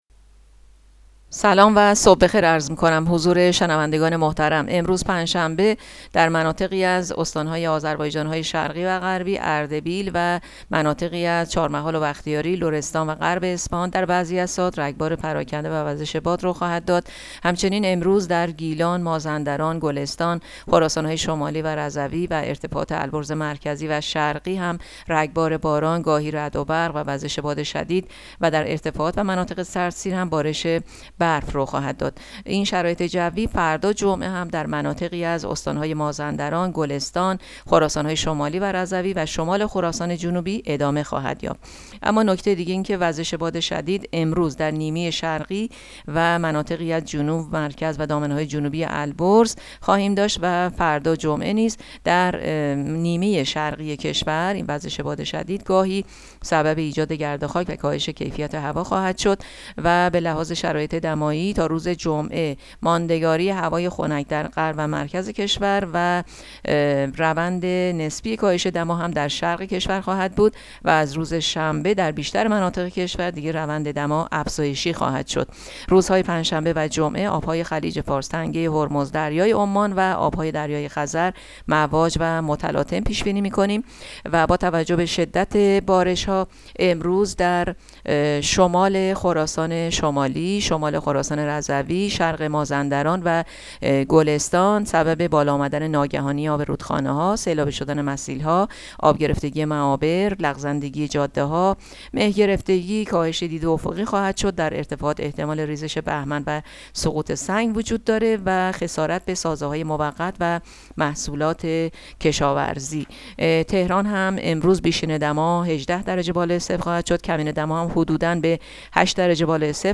گزارش رادیو اینترنتی پایگاه‌ خبری از آخرین وضعیت آب‌وهوای ۲۸ فروردین؛